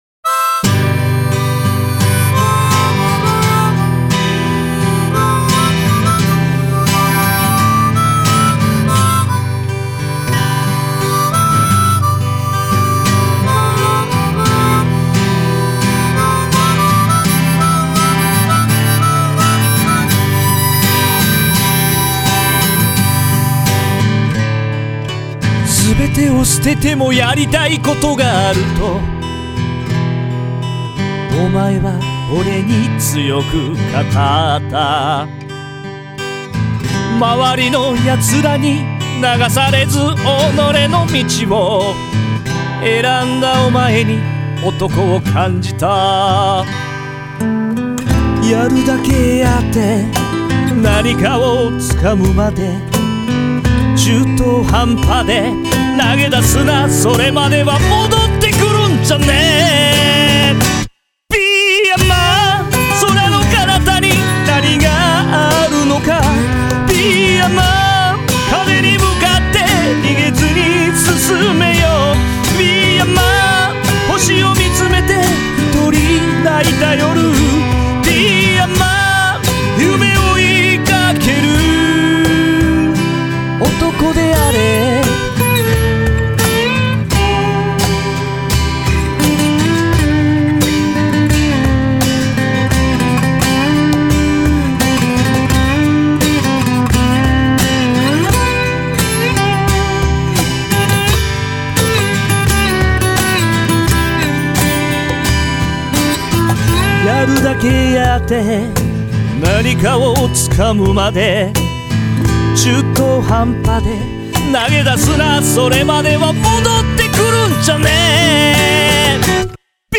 ☆オリジナル曲、「Be a MAN！